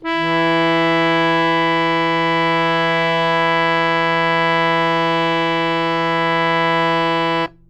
harmonium
E3.wav